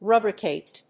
音節ru・bri・cate 発音記号・読み方
/rúːbrɪkèɪt(米国英語)/